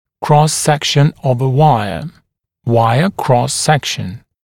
[krɔs-‘sekʃn əv ə ‘waɪə] [‘waɪə krɔs-‘sekʃn] [крос-‘сэкшн ов э ‘уайэ] [‘уайэ крос-‘сэкшн] поперечное сечение дуги, размеры поперечного сечения дуги (также wire cross-section)